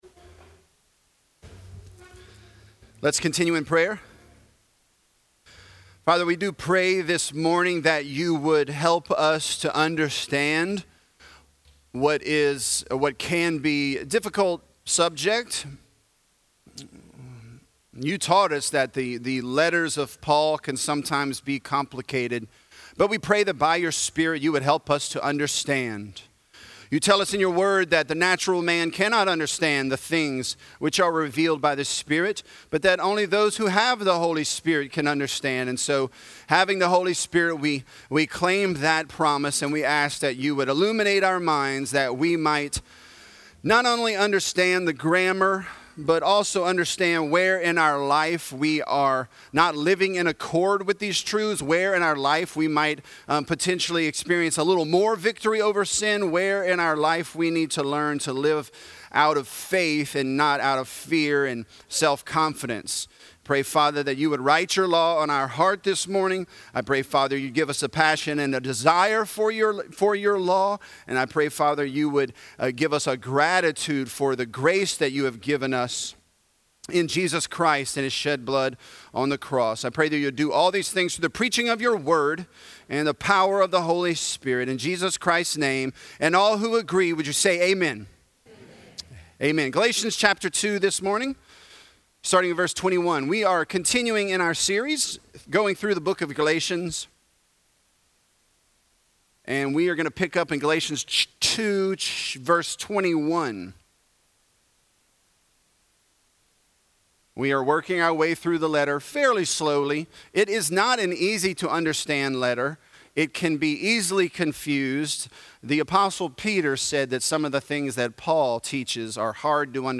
Galatians: Person not Performance | Lafayette - Sermon (Galatians 2)